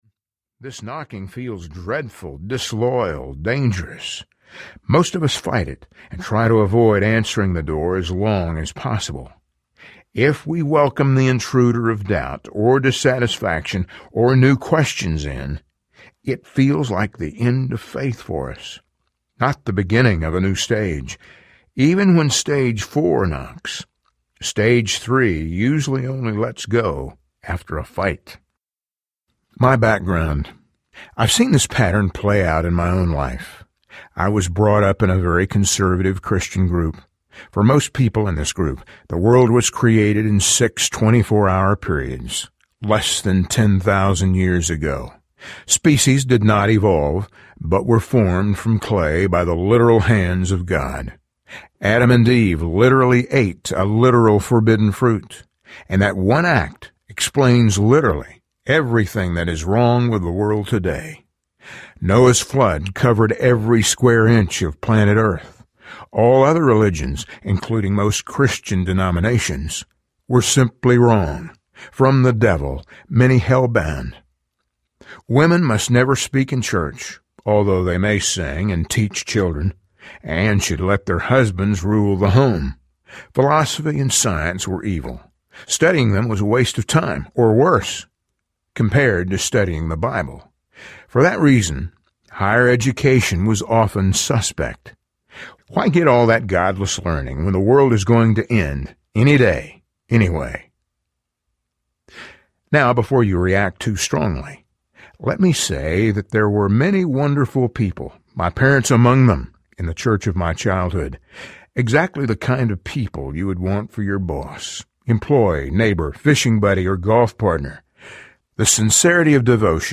Finding Faith: A Search for What Makes Sense Audiobook
5.3 Hrs. – Unabridged